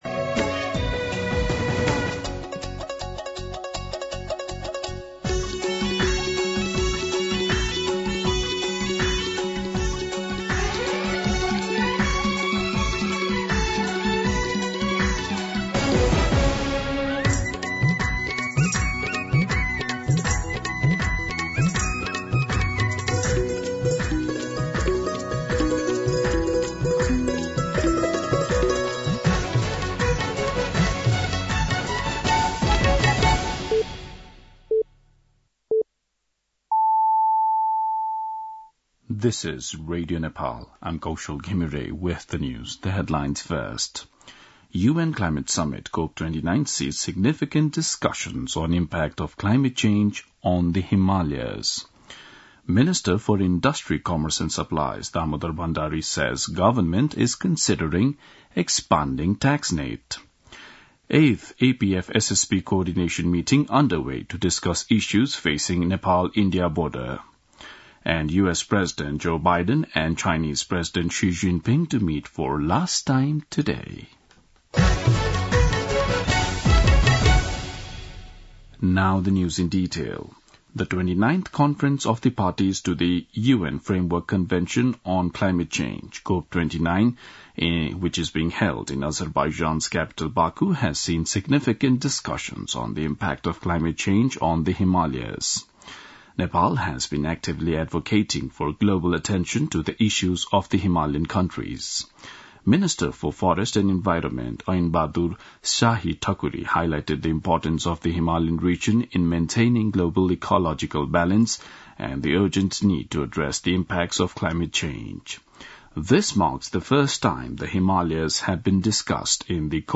दिउँसो २ बजेको अङ्ग्रेजी समाचार : २ मंसिर , २०८१
2-pm-English-News-3.mp3